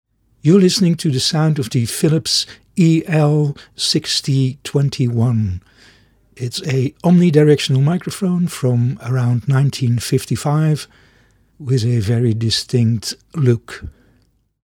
The Philips EL 6021 was an omnidirectional dynamic microphone, from around 1955.
These were certainly not meant as studio microphones, but their sound with a lot of mid-tones makes them sound vintage.
Philips EL 6021 sound UK.mp3